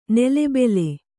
♪ nele bele